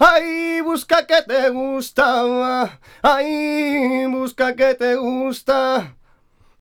Index of /90_sSampleCDs/USB Soundscan vol.59 - Spanish And Gypsy Traditions [AKAI] 1CD/Partition B/04-70C RUMBA